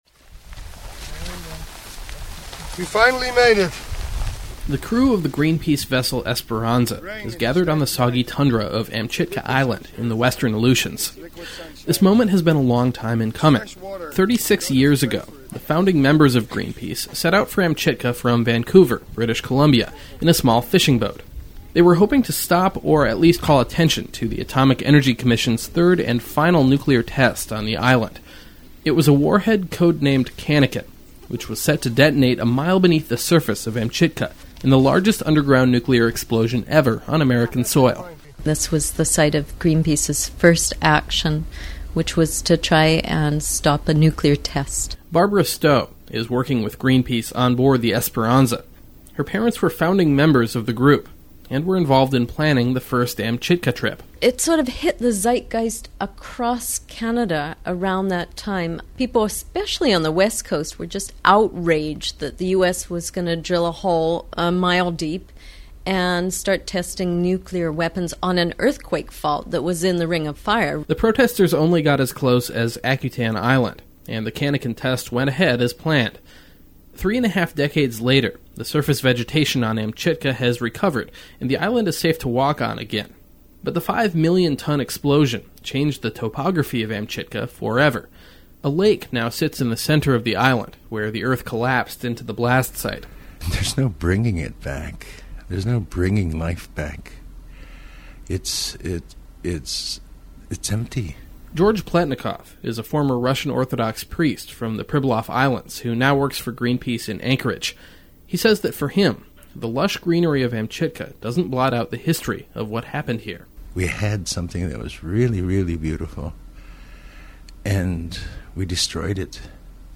reports from aboard the vessel Esperanza, anchored at Amchitka